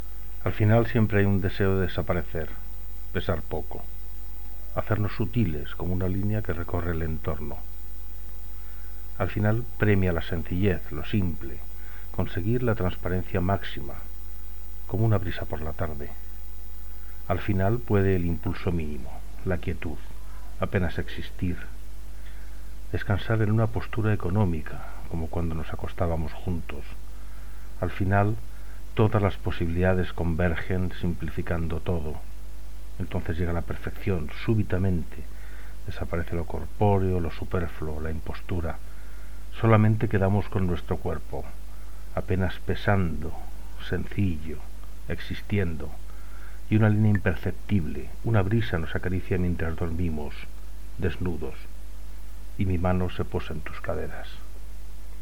Recitado del poema Al final